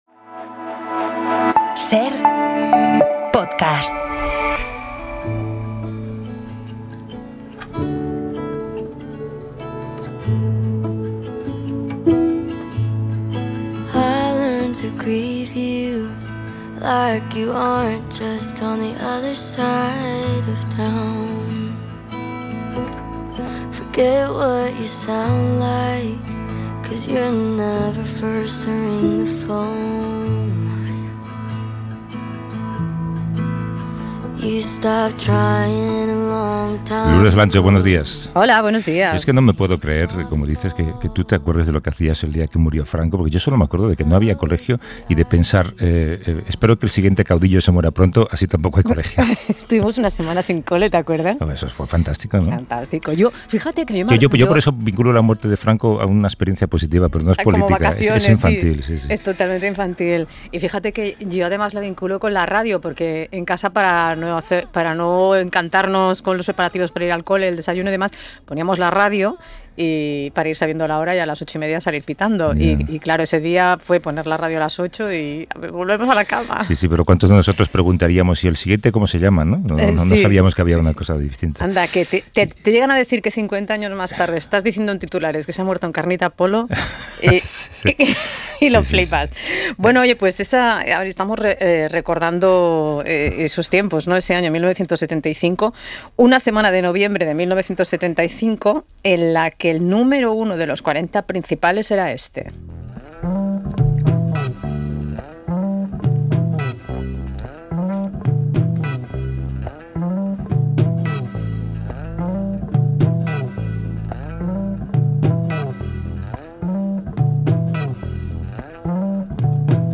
Con ellos visitamos la cárcel Modelo de Barcelona, ahora resignificada como escuela de primaria, centro cívico para el barrio y de memoria de la represión franquista.